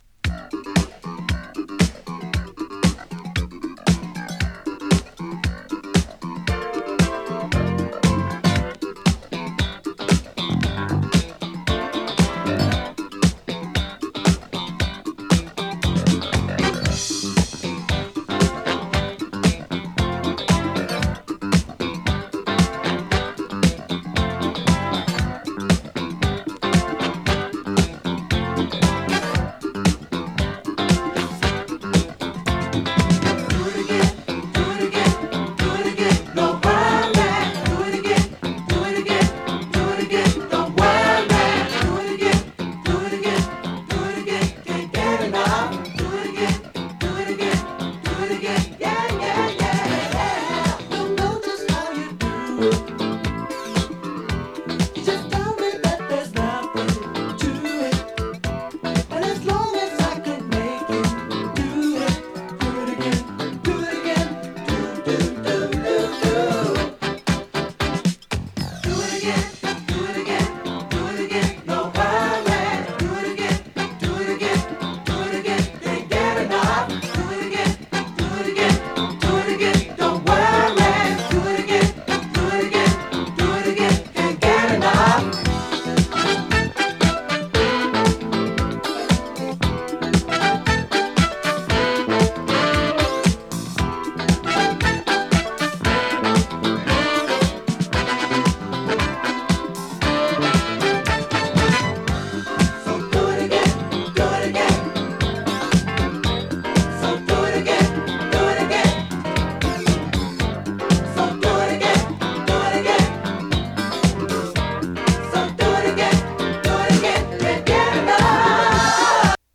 ユーロブギー
ユーロファンク